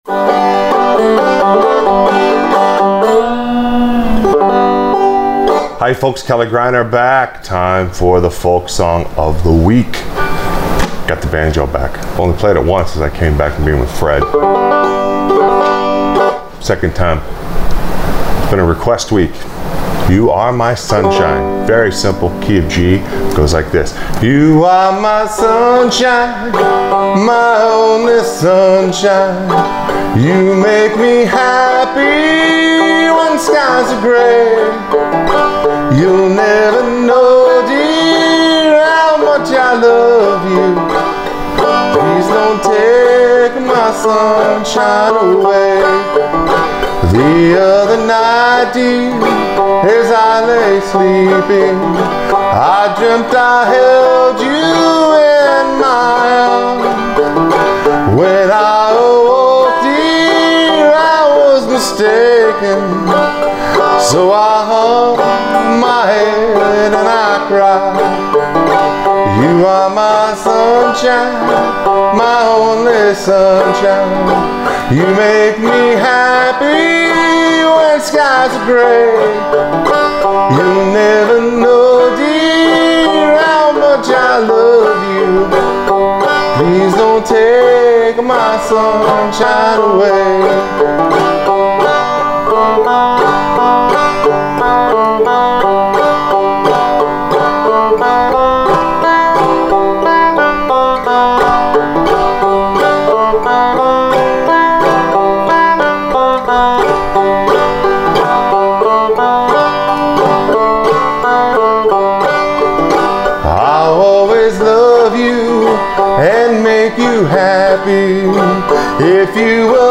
Frailing Banjo Lesson